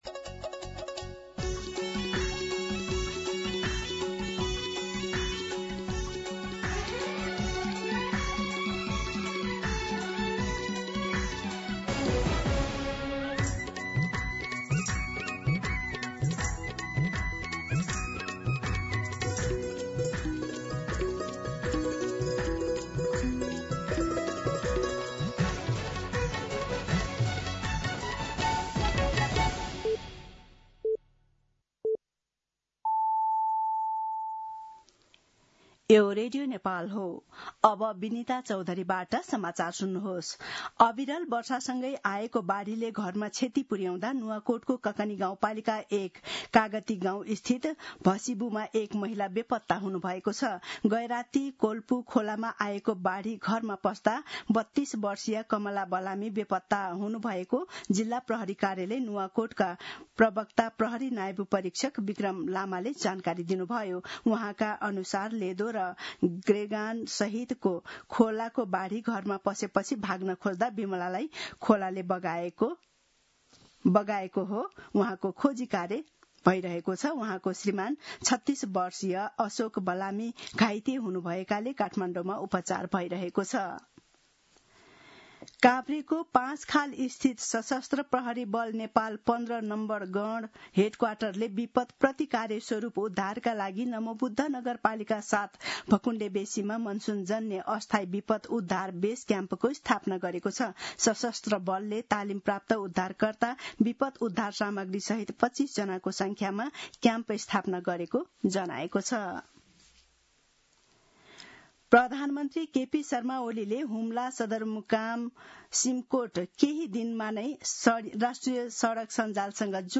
दिउँसो १ बजेको नेपाली समाचार : १३ असार , २०८२